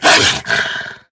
attack_hit_1.ogg